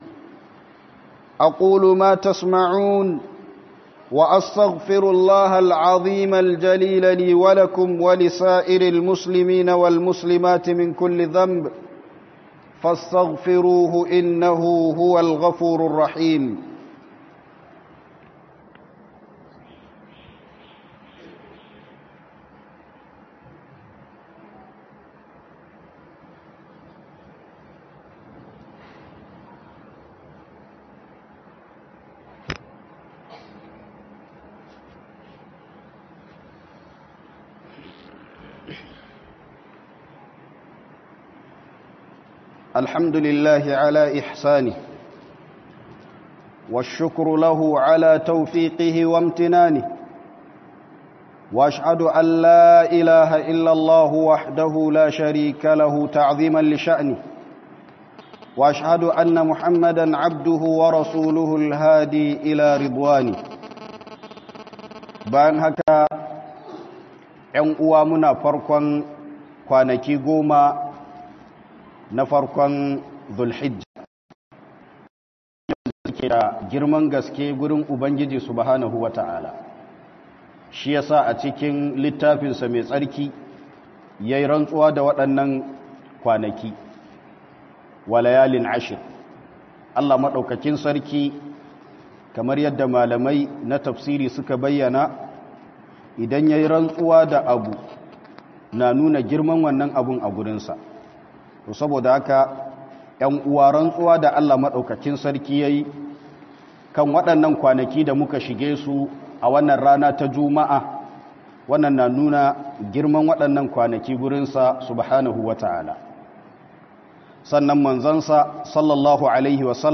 Falalar Kwanaki Goman Farkon Zul-hijjah - Huduba